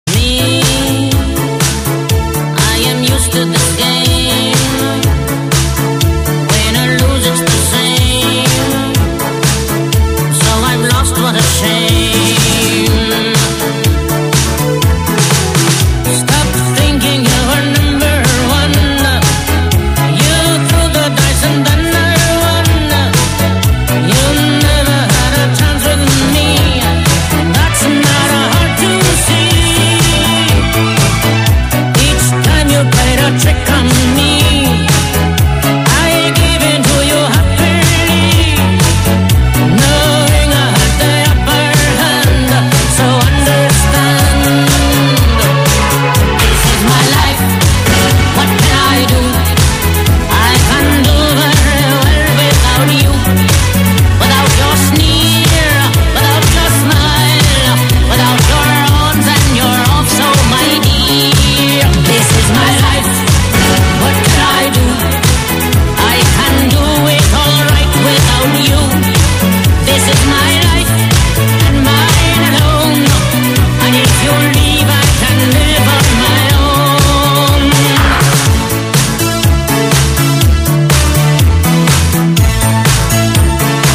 Pa nekdo žvižga v njej.